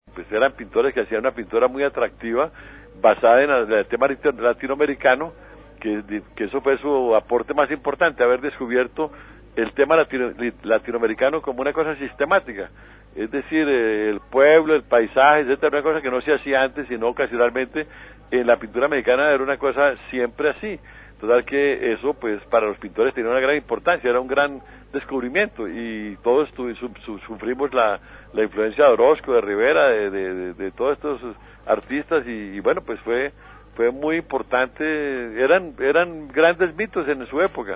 En Señal Memoria reposa una entrevista concedida por Fernando Botero a los micrófonos de la antigua Radiodifusora Nacional de Colombia.
Entrevista Fernando Botero - Muralismo mexicano - 2000.mp3